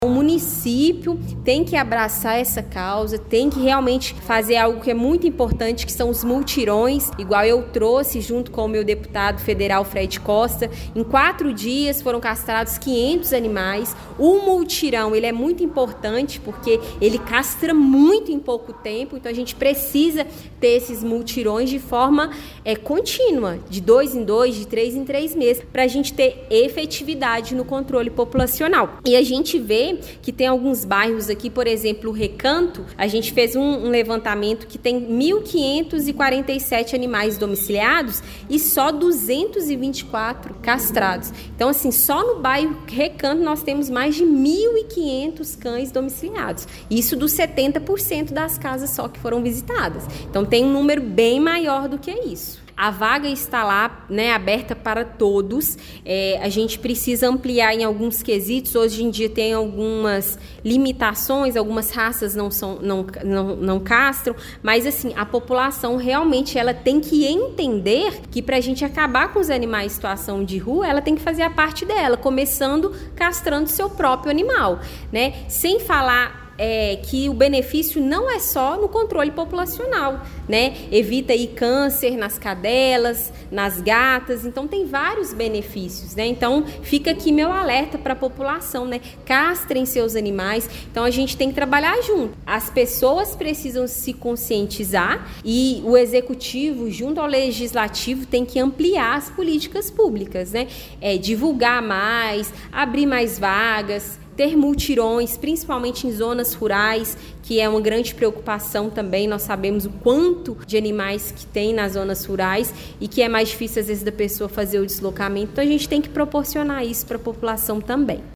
O Portal GRNEWS acompanhou a primeira reunião ordinária da Câmara Municipal em 2026, realizada nesta terça-feira, 20 de janeiro, a vereadora Camila Gonçalves de Araújo (PSDB), a Camila Mão Amiga, apresentou os resultados iniciais da primeira coleta do Censo Municipal de Animais Domésticos.